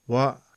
Wa - short vowel sound | 489_14,400